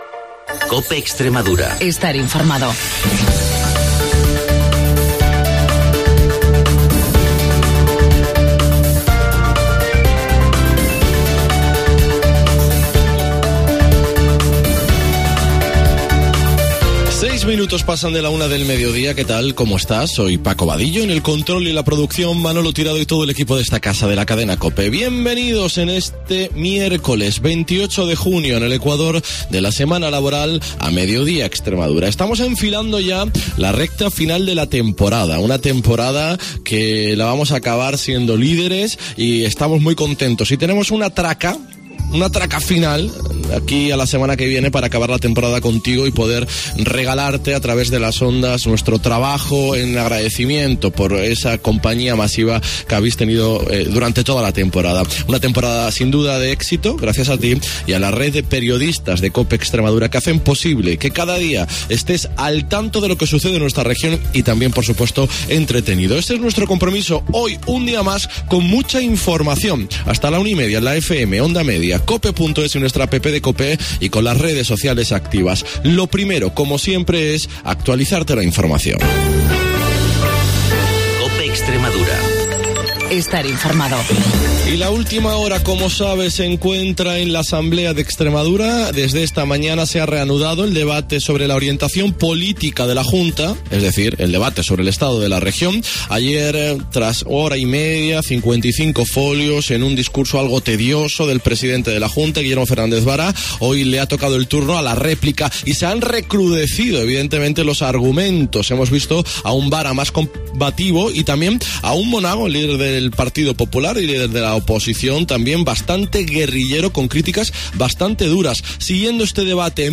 El programa líder de la radio extremeña
En el programa de hoy, entre otros asuntos, hemos hablado con el alcalde de Trujillo, Alberto Casero, para hablar de la programación de su Festival de Música Internacional.